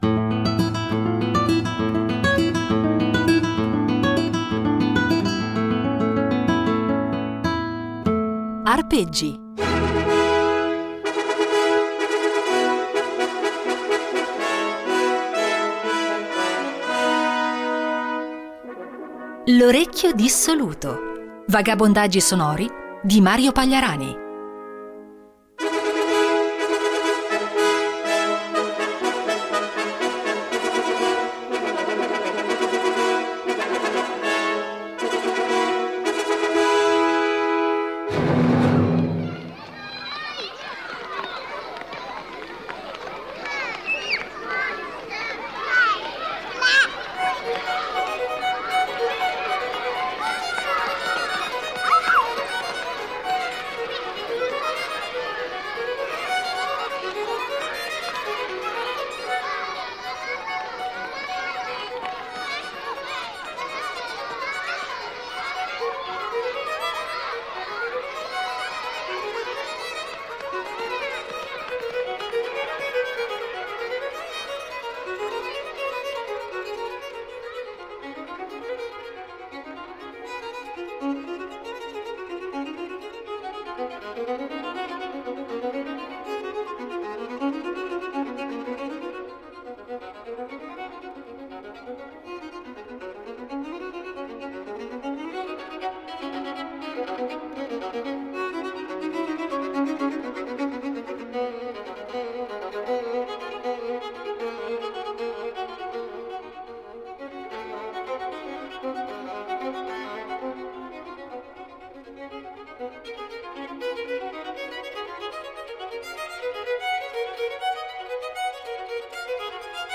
Un invito a perdersi tra suoni, rumori, e ascolti immaginifici che si rincorrono senza meta.